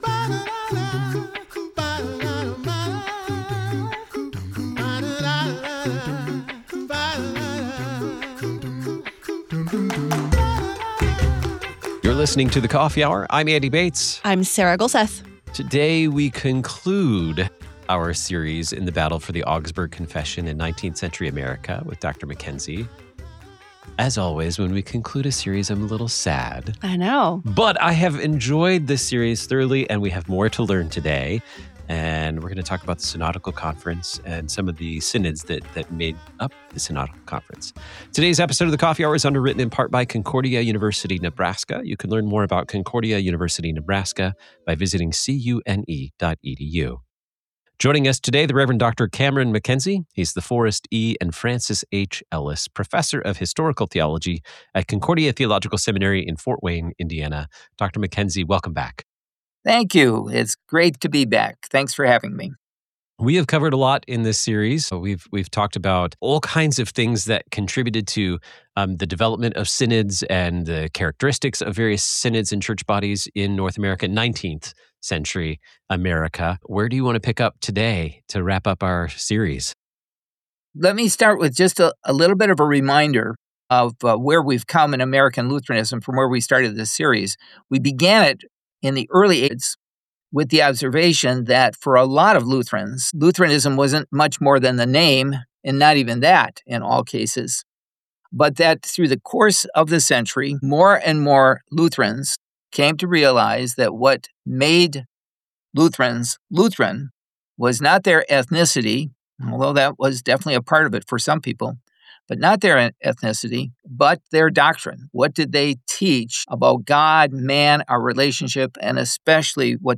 Their conversation emphasizes the importance of unity in doctrine and practice among Lutherans.